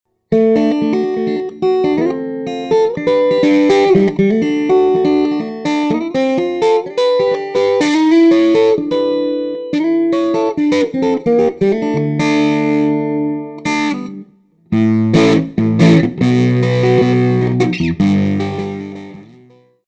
I used my PC headset mike direct into a Toshiba laptop.
I added some reverb to Cut 4, which sounds more like it sounded in my practice room, very sweet, full, and packed with harmonics.
Light touch start to a bit of grind, reverb added (sounds more like it sounded to my ears)
light2roughReverb.mp3